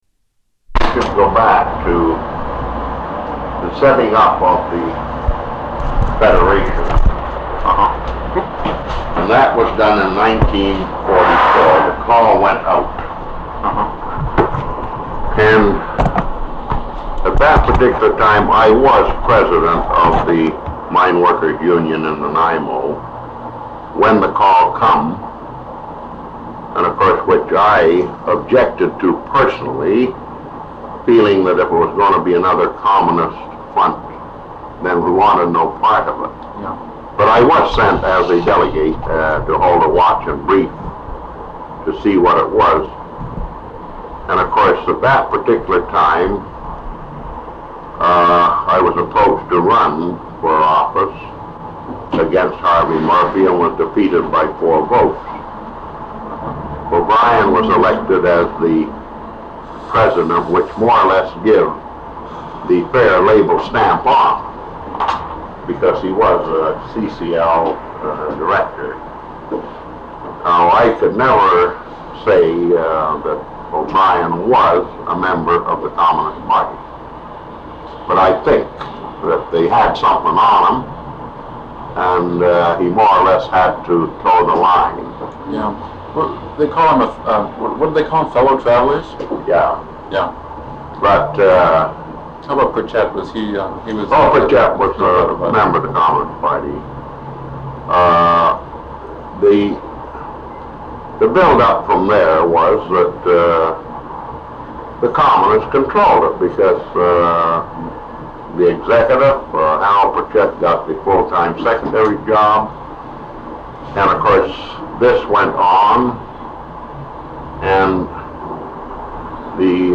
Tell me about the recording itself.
NOTE: The quality of this recording is poor; it has been restored to the best of our ability.